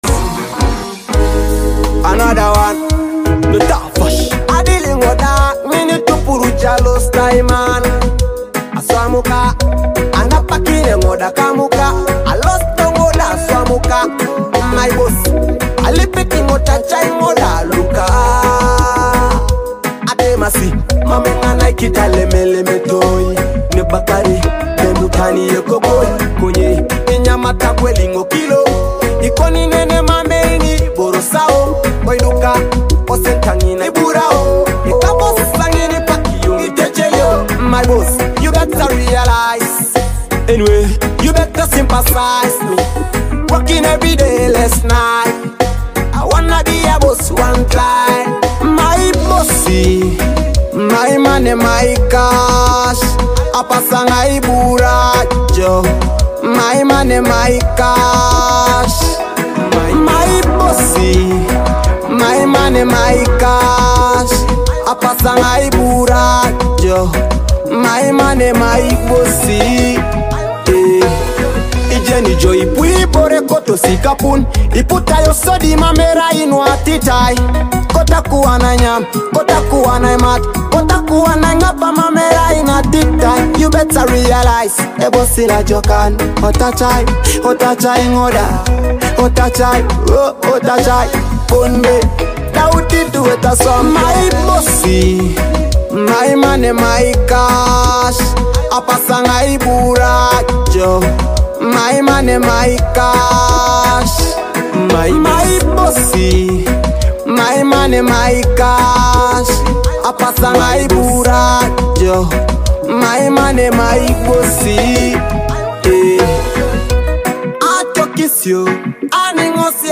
a high-energy track that’s sure to get you moving!
infectious beats
vibrant rhythms and catchy melodies